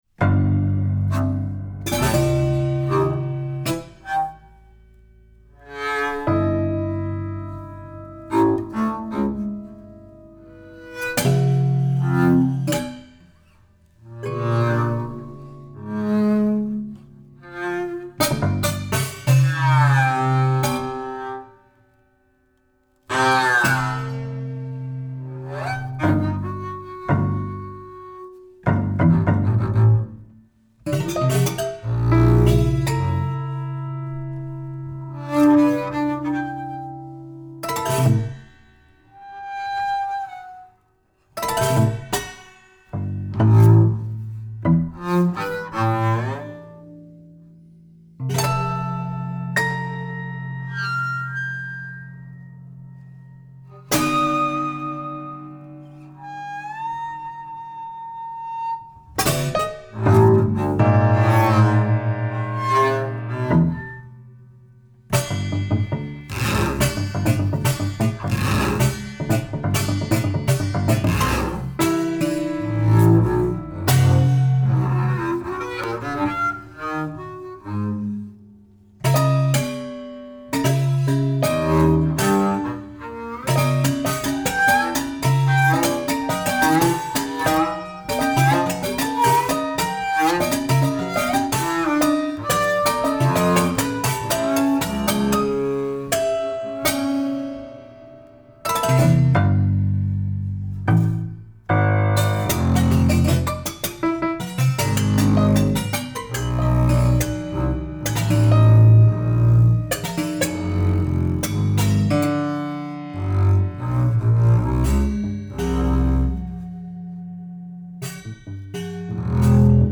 double bass
prepared and unprepared piano